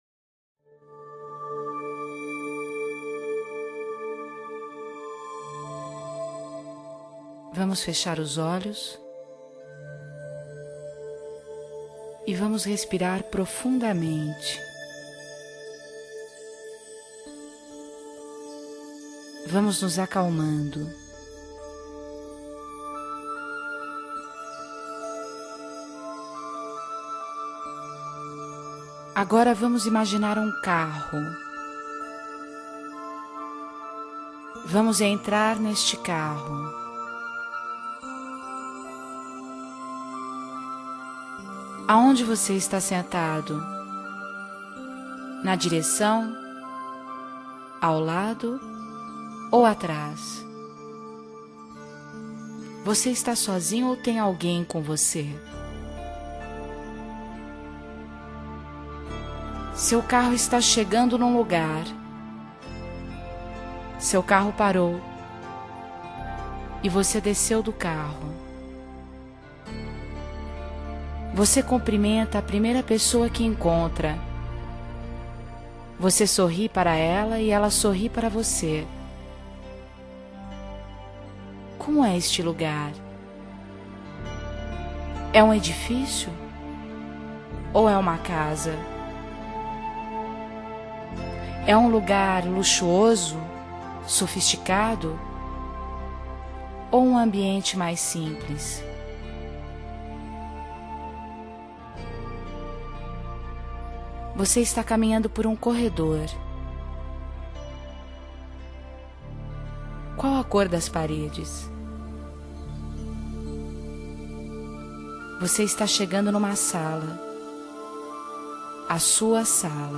Relaxamento
relaxamento.mp3